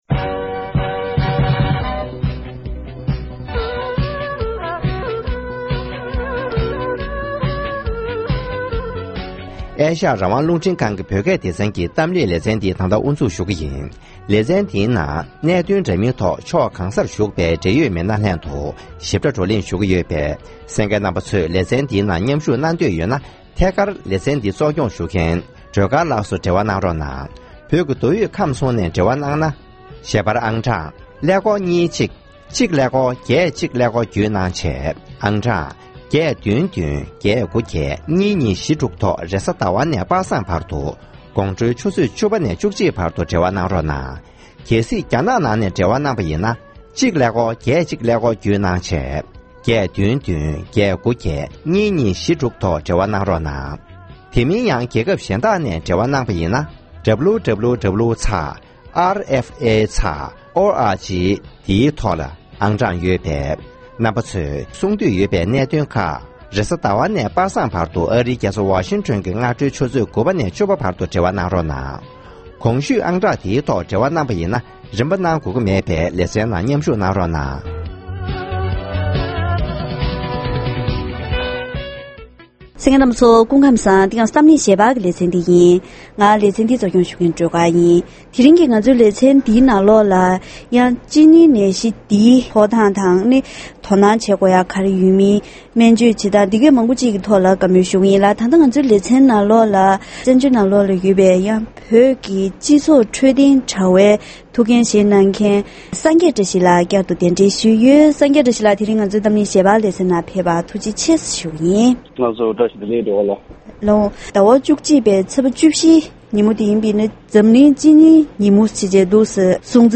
༄༅། །ཐེངས་འདིའི་གཏམ་གླེང་ཞལ་པར་ལེ་ཚན་ནང་ལོ་ལྟར་སྤྱི་ཟླ་༡༡ཚེས་༡༤ཉིན་ནི་འཛམ་གླིང་གཅིན་སྙིའི་ཉིན་མོར་གཏན་འབེབས་ཐོག་སྔོན་འགོག་གི་དྲིལ་བསྒྲགས་བྱེད་ཀྱི་ཡོད་པ་བཞིན་བོད་པའི་སྤྱི་ཚོགས་ནང་གཅིན་སྙིའི་ནད་གཞི་ཕོག་མཁན་ཇི་འདྲ་ཡོད་མེད་དང་། བོད་པ་ཚོས་དོ་སྣང་ངང་སྔོན་འགོག་དང་བརྟག་དཔྱད། སྨན་བཅོས་བྱ་ཕྱོགས་ཐོག་འབྲེལ་ཡོད་མི་སྣ་དང་བཀའ་མོལ་ཞུས་པ་ཞིག་གསན་རོགས་གནང་།